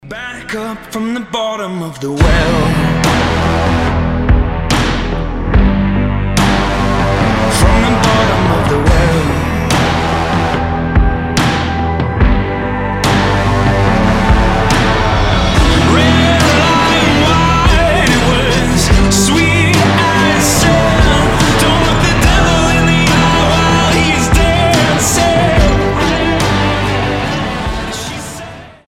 • Качество: 320, Stereo
alternative
indie rock